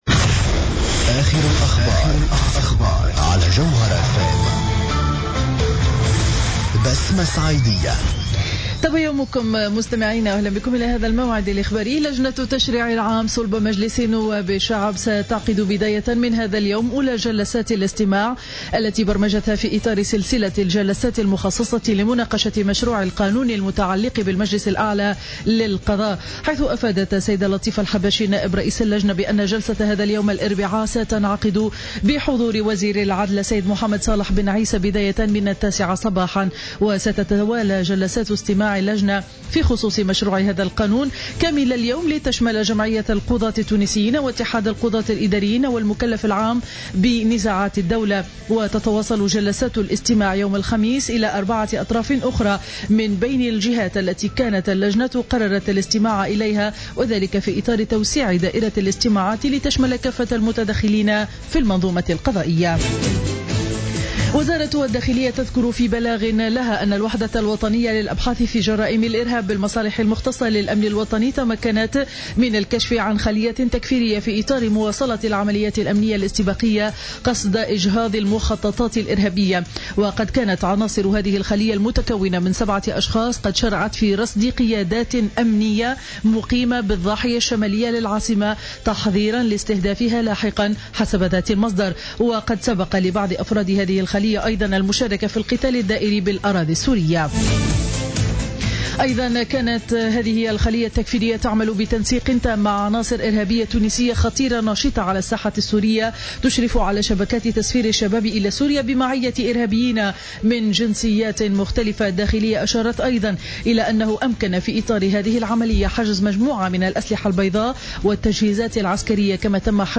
نشرة أخبار السابعة صباحا ليوم الاربعاء 18 مارس 2015